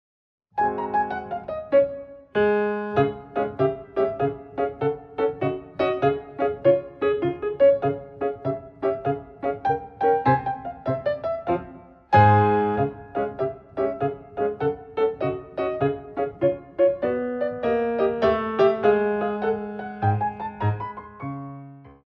16x8 - 6/8